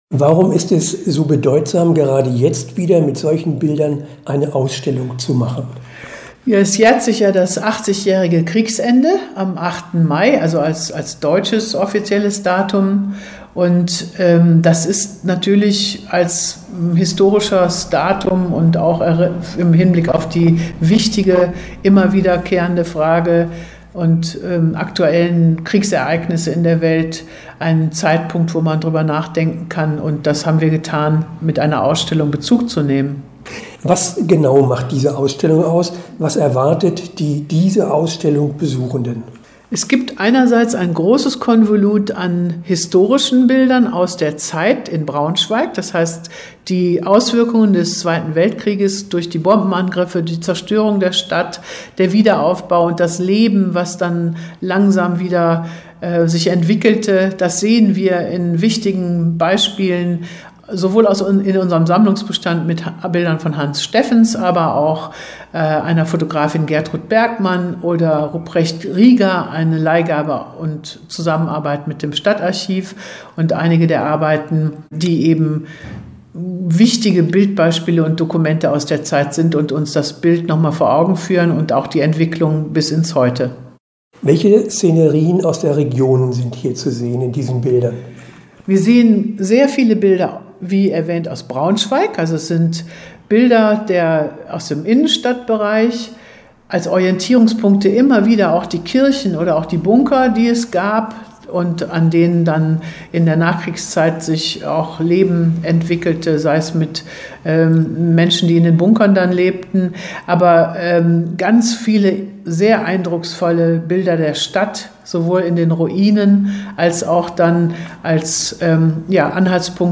Interview-Erinnerung-im-Jetzt.mp3